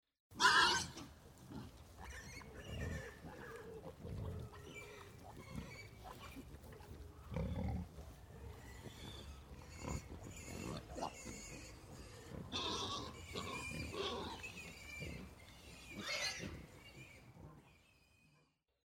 Karty aktywności - głos dzika - EDURANGA
ka2_s_15_dzik.mp3